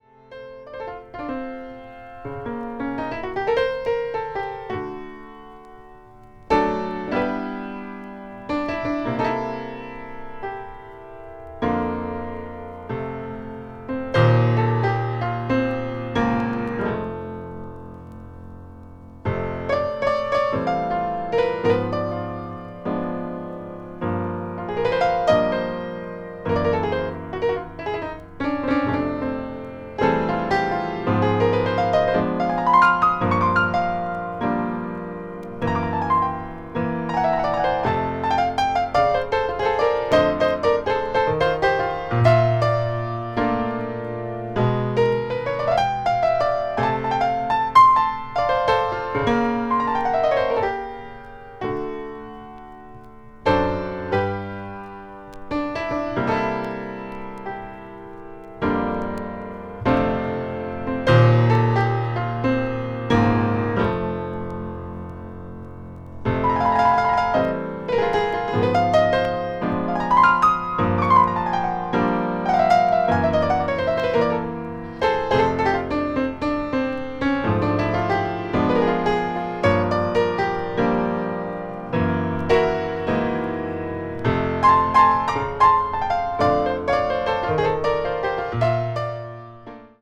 media : VG/VG(細かい擦れ/スリキズによるチリノイズが入る箇所あり)
全編ピアノ・ソロを収録した作品で
contemporary jazz   deep jazz   piano solo